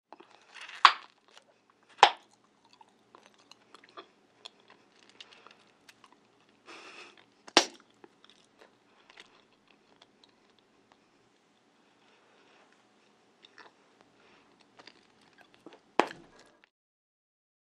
DINING - KITCHENS & EATING BUBBLE GUM: INT: Popping & cracking.